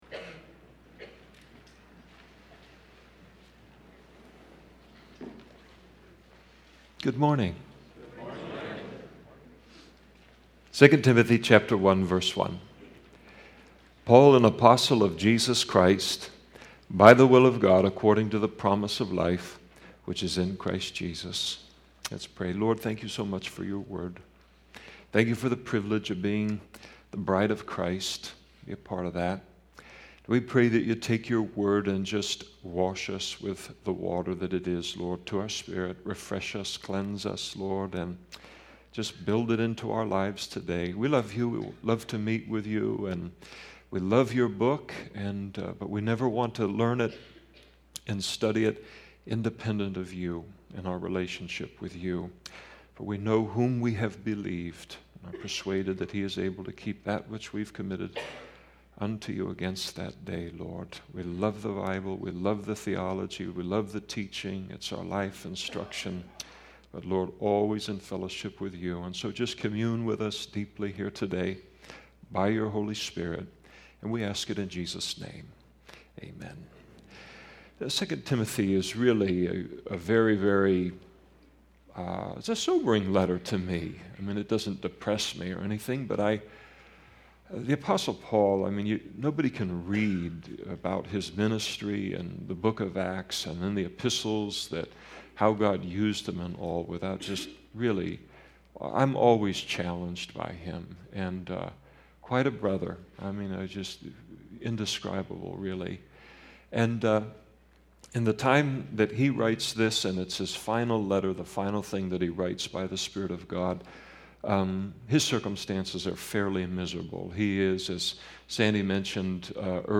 2007 DSPC Conference: Pastors & Leaders Date
2007 Home » Sermons » Session 7 Share Facebook Twitter LinkedIn Email Topics